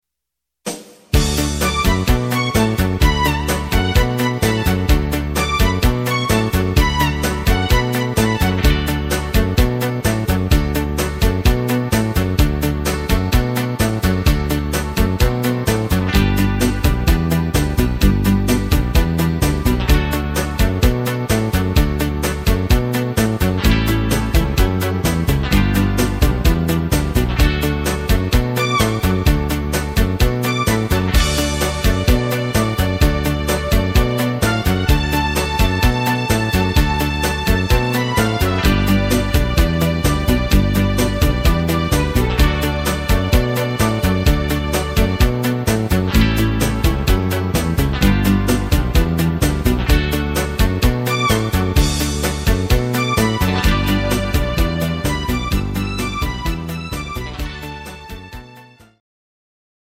Rhythmus  Rock'n Roll
Art  Duette, Englisch, Oldies